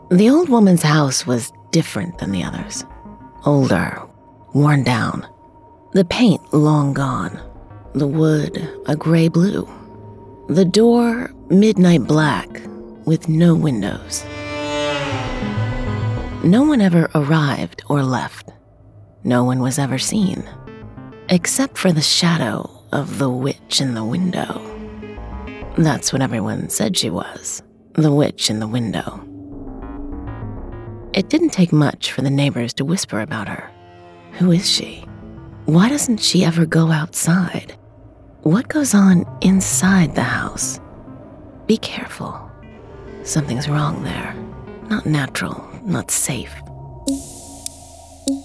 Narração
A maioria do meu trabalho apresenta um dialeto americano neutro, mas também posso fornecer regionalismos conforme necessário.
Rode NT1
Mezzo-soprano